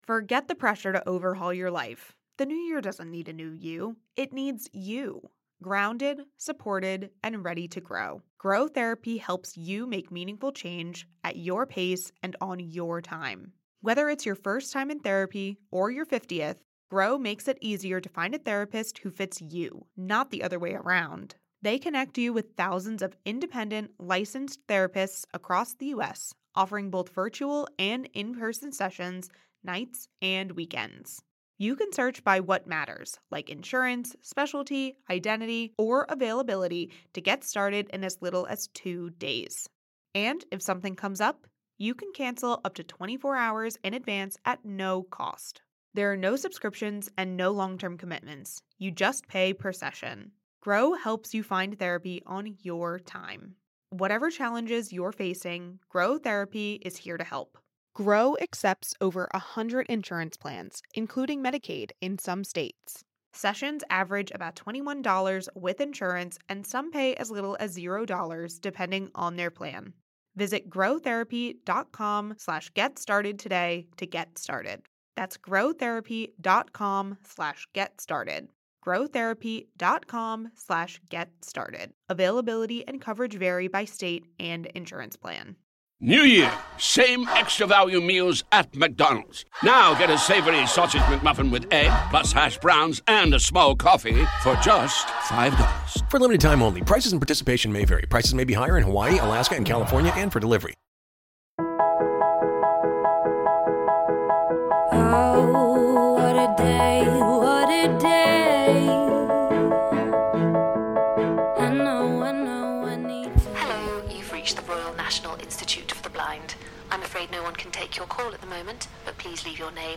Lydia is Patsy Ferran Meg is Mandeep Dhillon